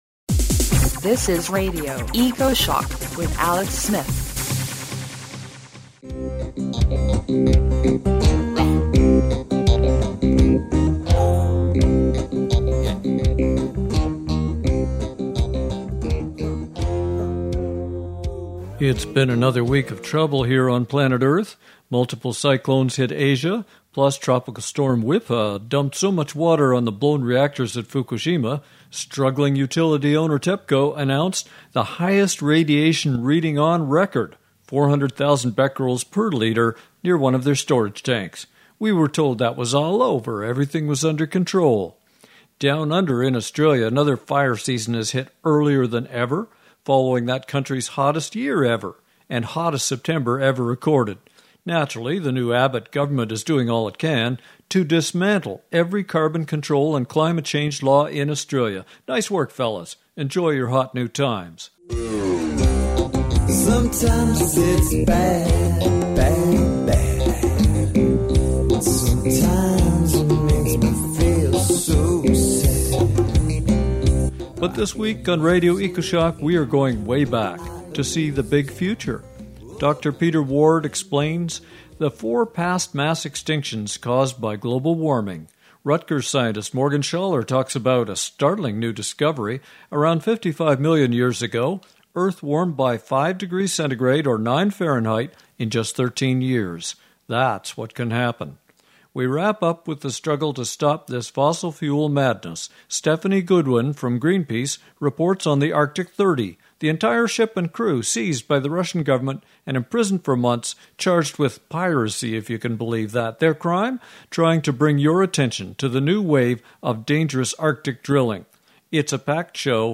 Is “epigenetics” an opportunity or a curse? Dr. Peter Ward, University of Washington on his new book “Lamarck’s Revenge”. Plus the most moving climate speech 2018, Canadian Green Party Leader Elizabeth May speaks to Parliament.